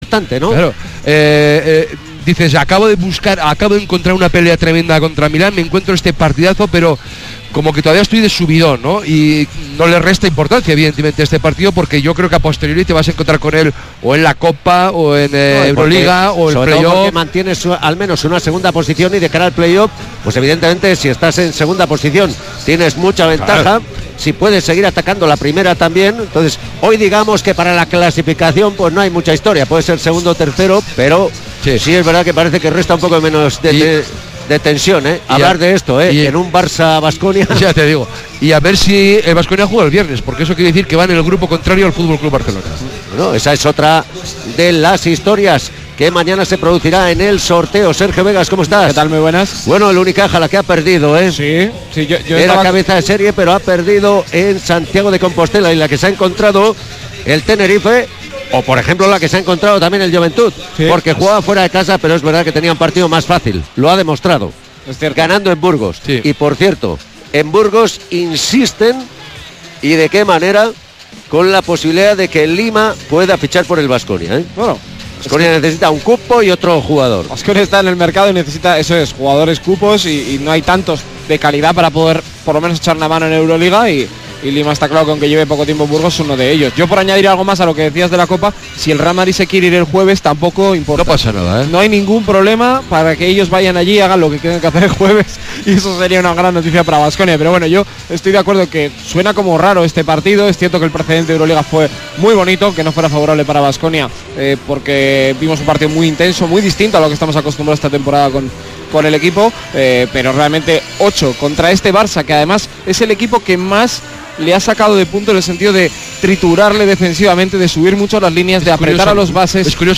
Kirolbet Baskonia-Barca jornada 17 ACB 2018-19 retransmisión completa Radio Vitoria